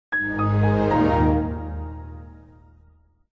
microsoft-windows.mp3